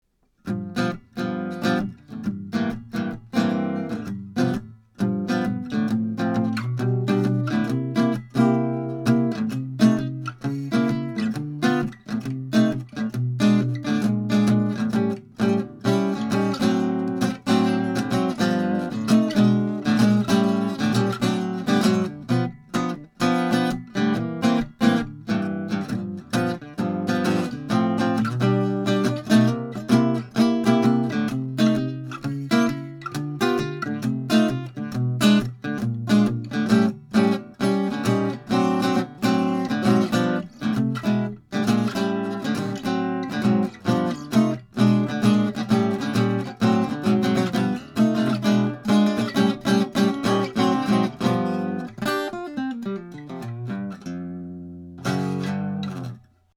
Tracked through a pair of Warm Audio WA12 preamps, into a Metric Halo ULN-8 interface, no compression, EQ or effects:
1933 GIBSON L10 ARCHTOP